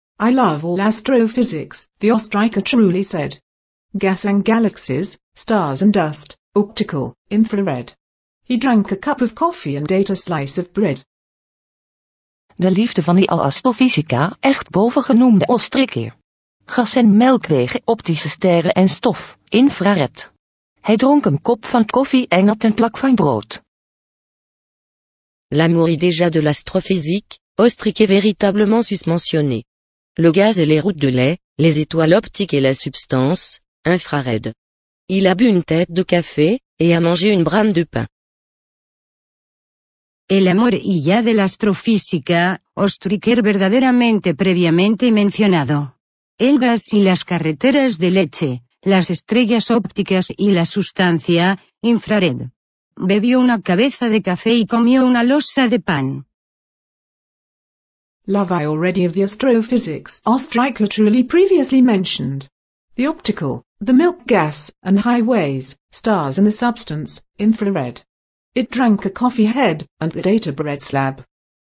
Alice And the Space Telescope - text-to-speech